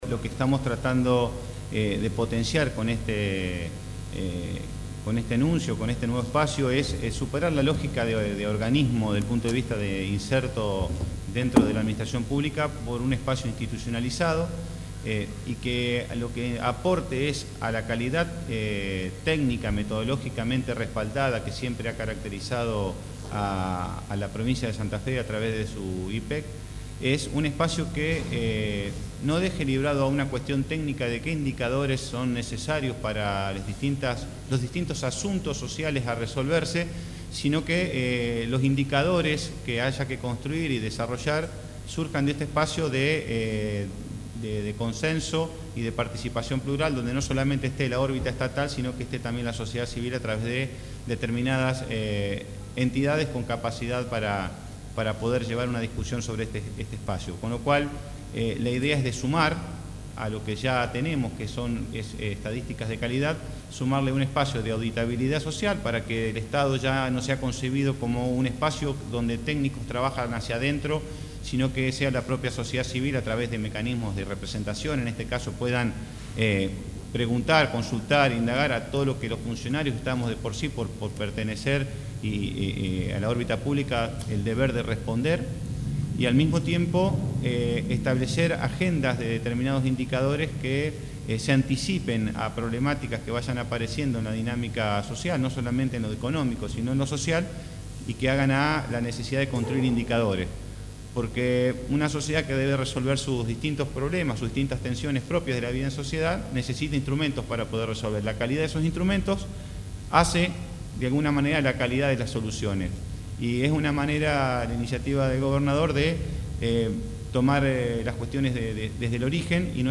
El gobernador de Santa Fe, Miguel Lifschitz, junto al ministro de Economía, Gonzalo Saglione, y el secretario de Finanzas, Pablo Olivares, anunció este martes la creación del Observatorio Estadístico Provincial (OEP) para la elaboración de estadísticas e índices de precios propios de la provincia, en una conferencia de prensa realizada en Casa de Gobierno de la ciudad de Santa Fe.